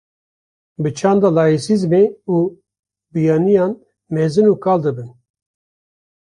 /kɑːl/